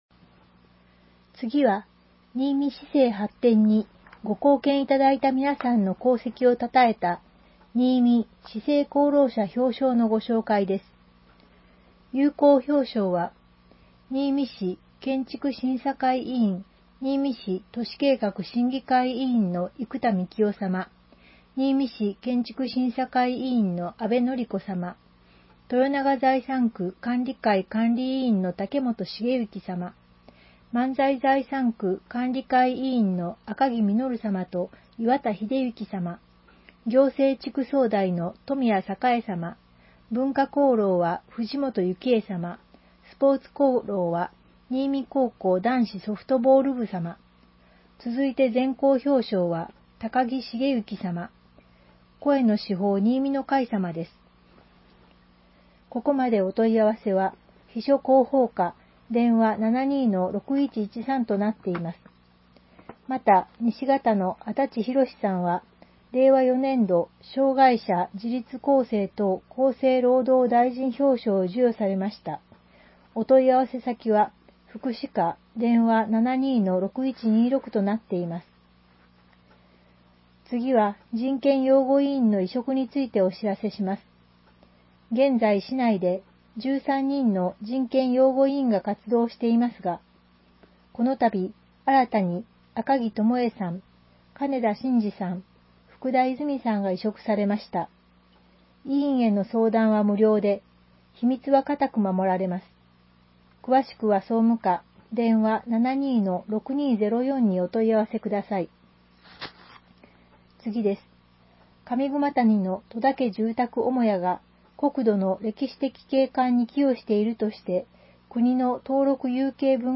声の市報にいみの会から、声の市報１月号を提供いただきました。
市報にいみ１月号、社協だより第108号の概要を音声でお伝えします。